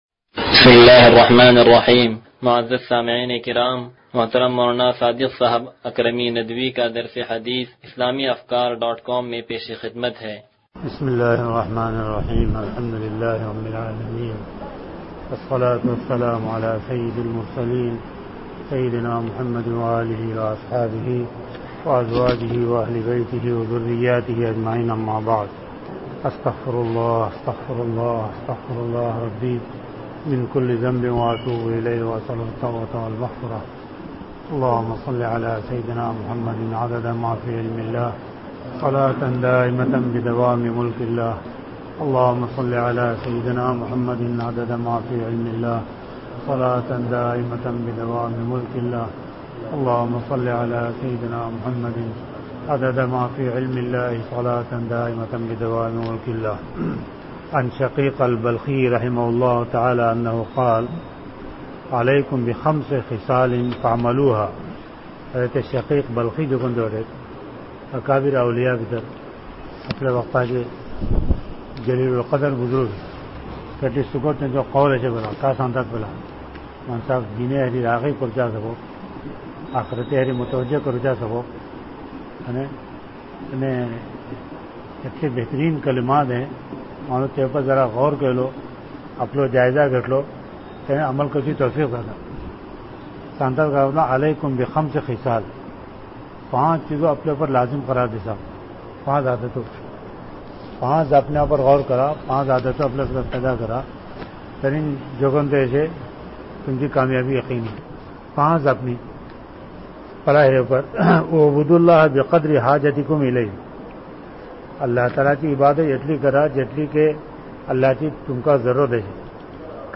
درس حدیث نمبر 0132